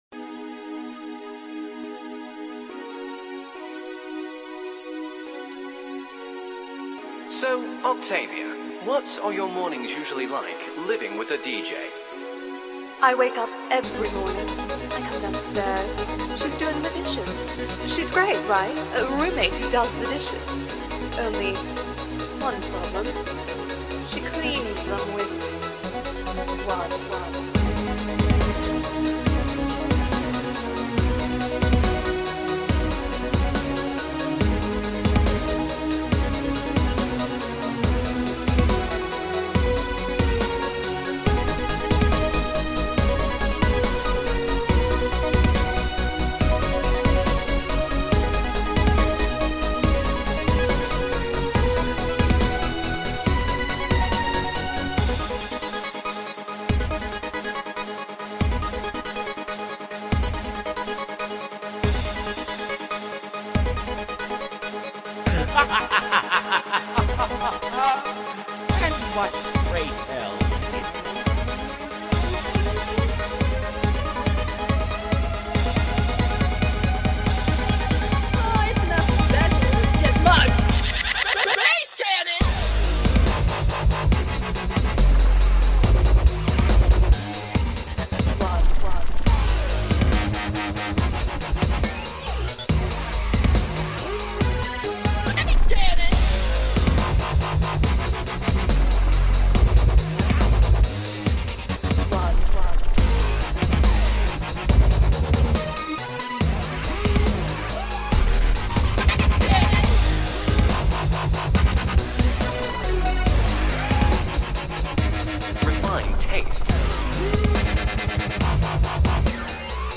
This is my 1st Dubstep track made with Logic Pro.